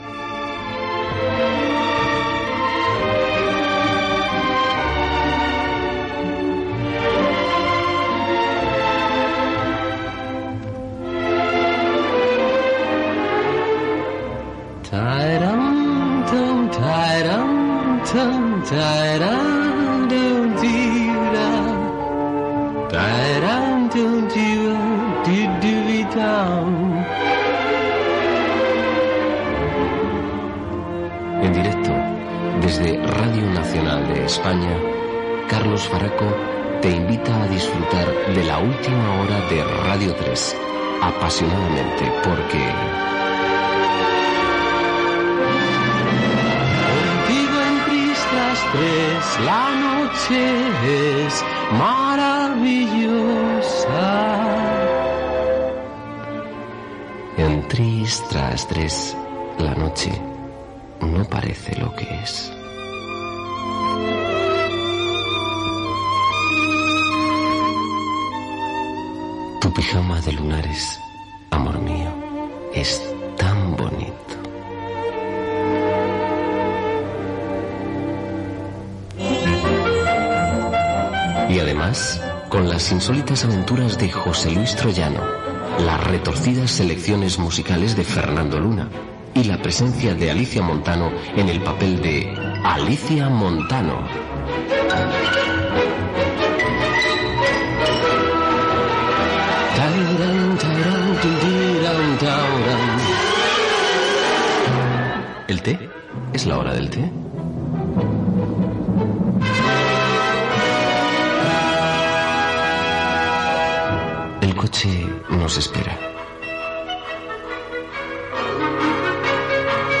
Primera edició del programa. Presentació, sumari de continguts i equip, comentaris i temes musicals
Entreteniment
FM